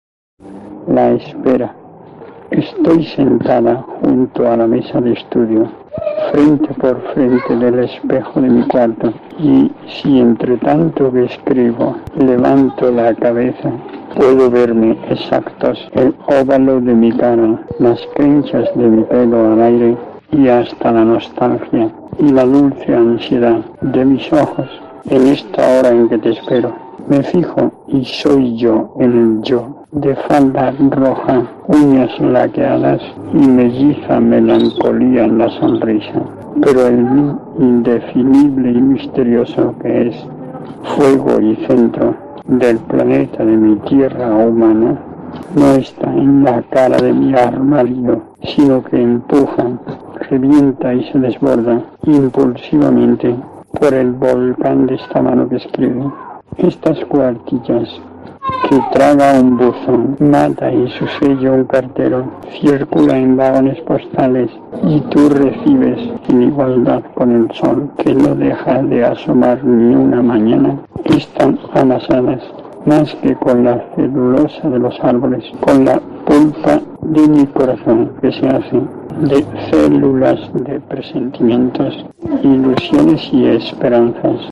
Así que la importancia de este documentos es tal, que más allá de la calidad del sonido, podemos asistir a la creación literaria de alguién que no resignó nunca, por más que la gravedad de sus enfermedad le pusiera obstáculos e impedimentos, y siguió con su actividad intelectual y de la que, gracias a esta histórica grabación, podemos ser unos espectadores privilegiados
Con una voz firme va describiendo como, a pesar de las limitaciones de movimiento que sufre, es capaz de verse en el espejo y saludar la llegada de un nuevo día.